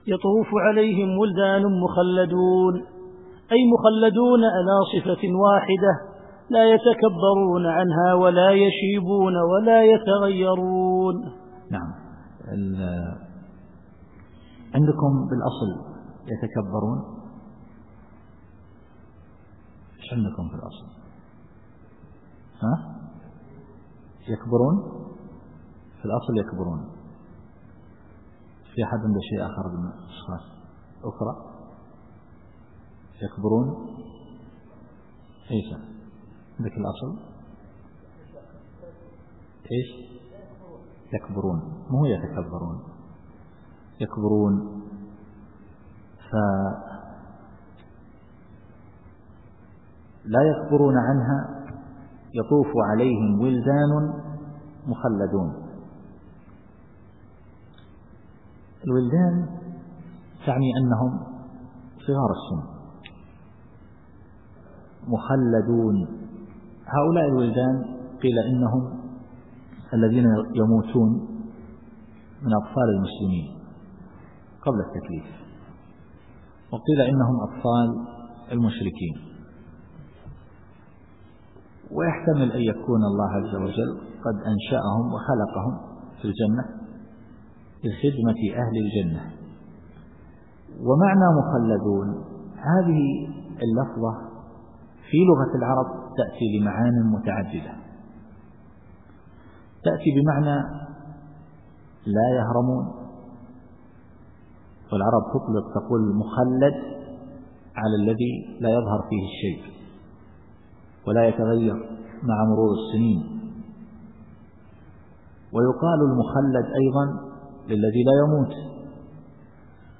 التفسير الصوتي [الواقعة / 17]